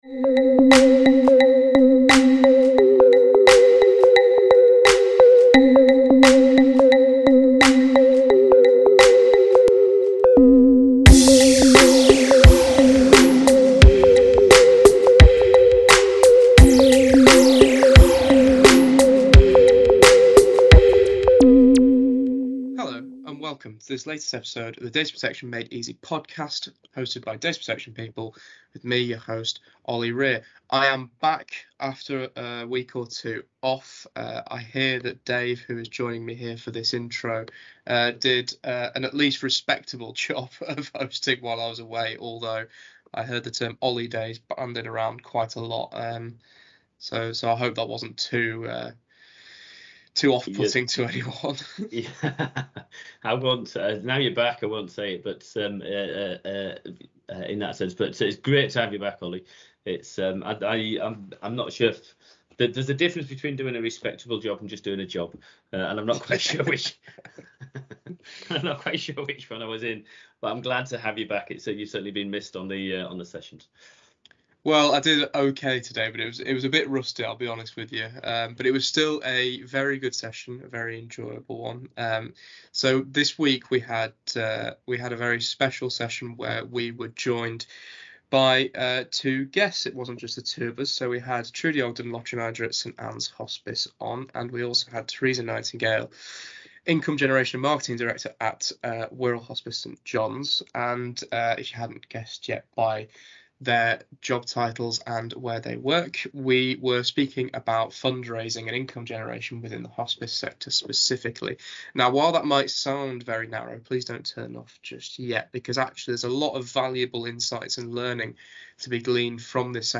This week's episode is extra special as we are joined by two amazing guest speakers who have extensive experience with data protection in hospices.